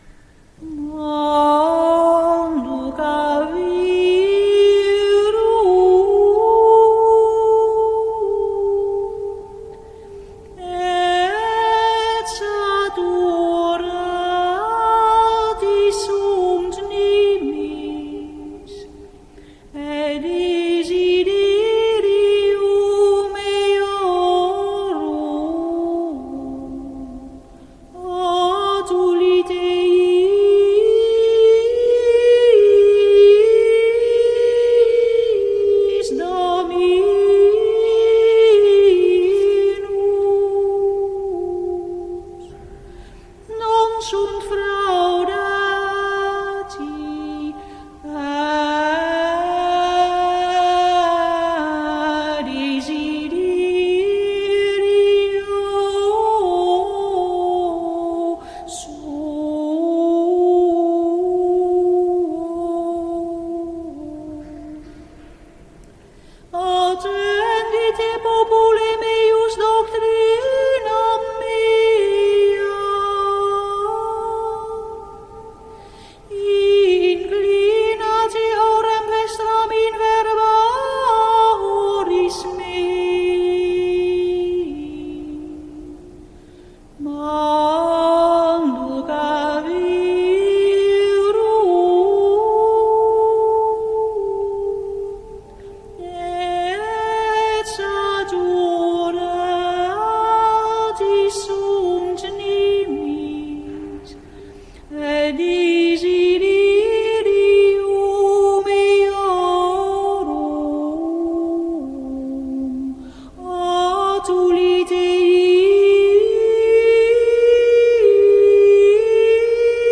Manducaverunt (Ps 77, 29-30), communio  WMP   RealPlayer